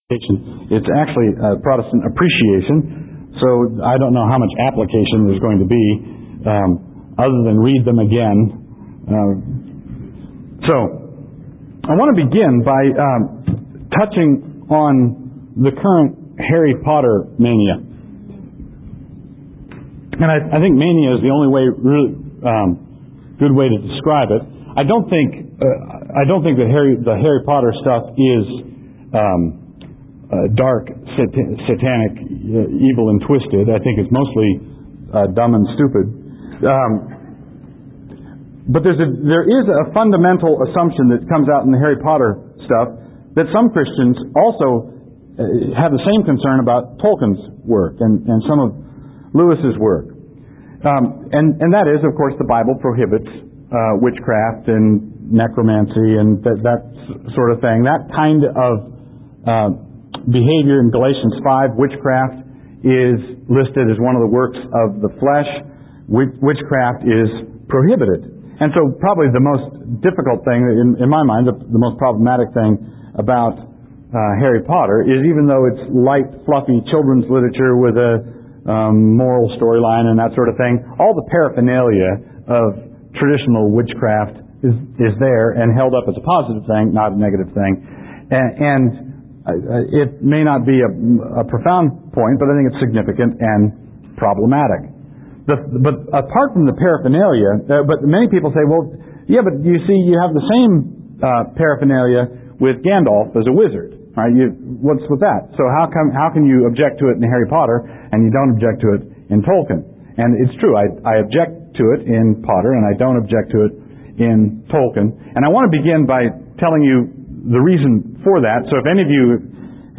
2003 Workshop Talk | 0:51:02 | All Grade Levels, Literature
Additional Materials The Association of Classical & Christian Schools presents Repairing the Ruins, the ACCS annual conference, copyright ACCS.